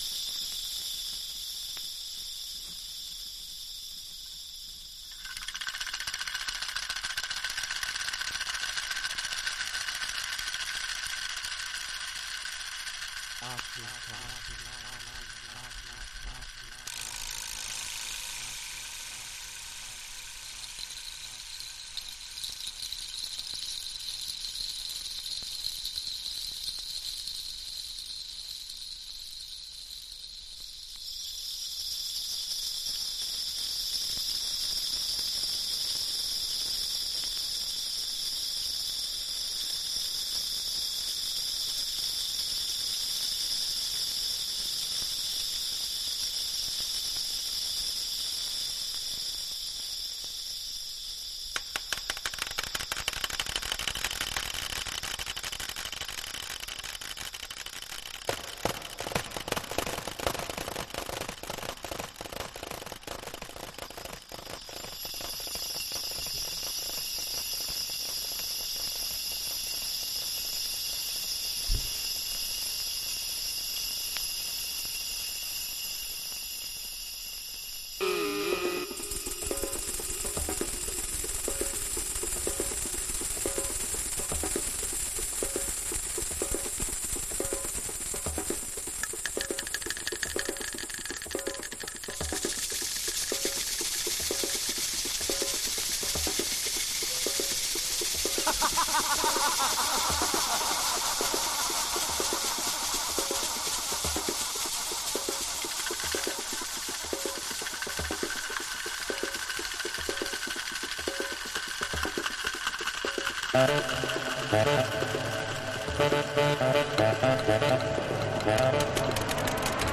Early House / 90's Techno
オリジナルは92年のリリース、90's Deep House Classic。